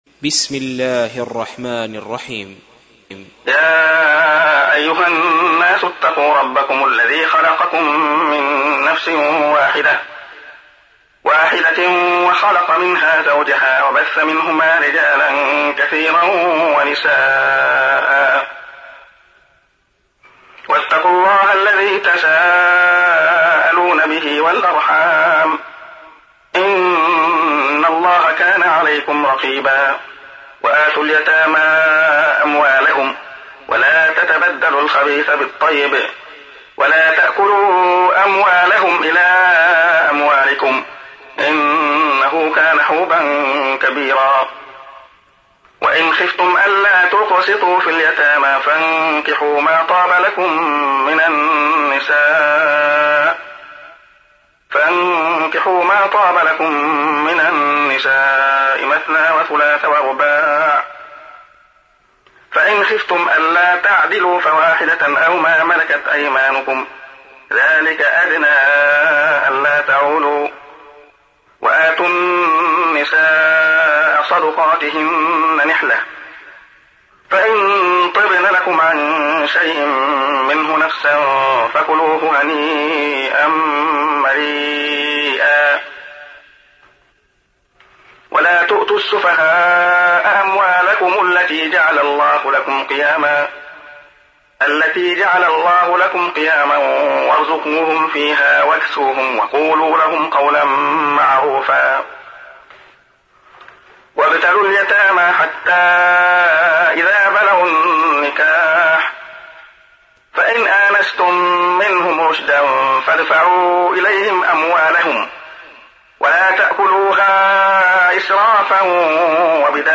تحميل : 4. سورة النساء / القارئ عبد الله خياط / القرآن الكريم / موقع يا حسين